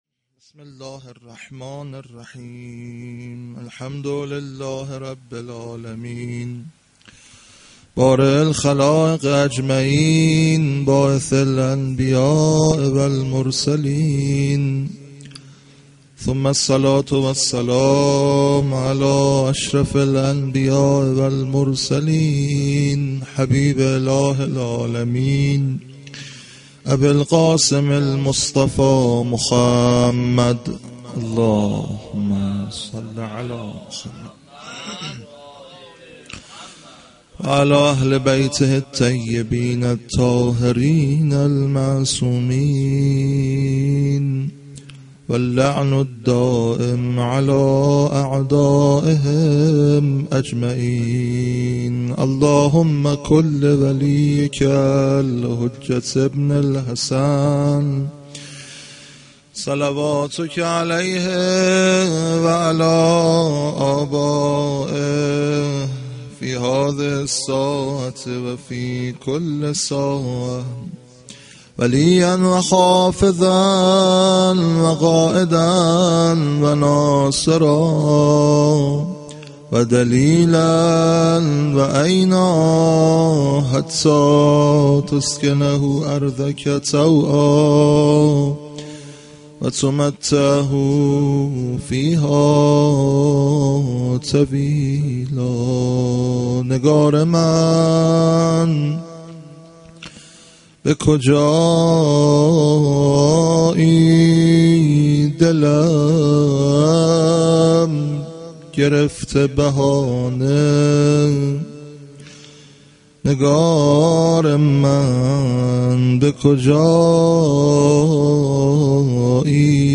vafate-h.-khadijeh-s-93-sokhanrani.mp3